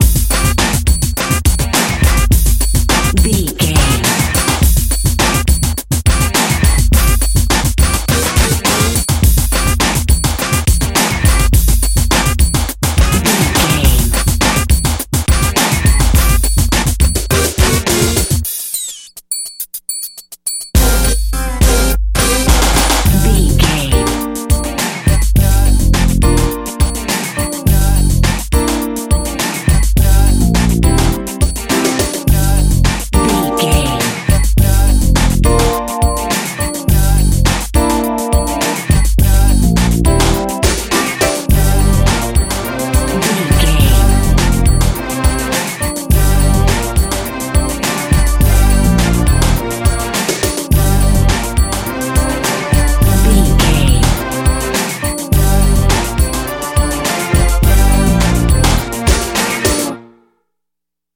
Uplifting
Aeolian/Minor
Fast
drum machine
synthesiser
electric piano